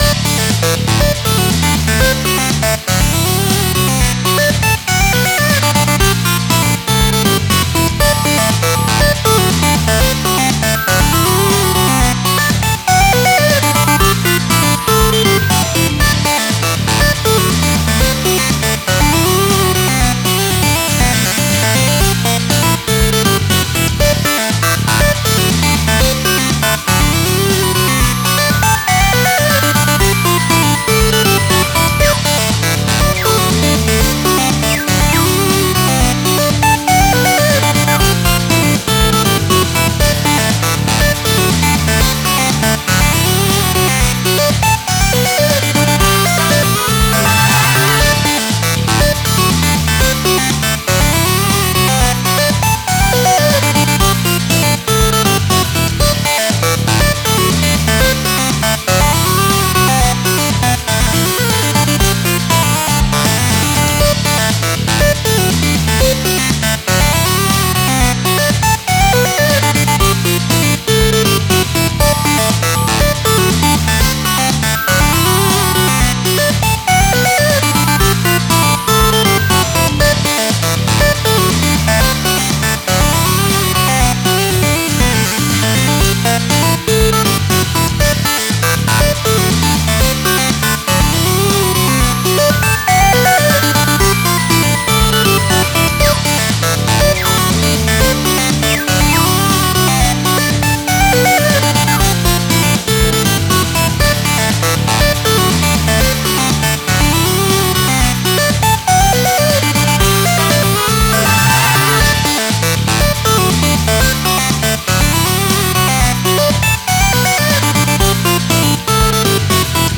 明るい雰囲気の曲です。
RPGのリザルト画面なイメージです
タグ あかるい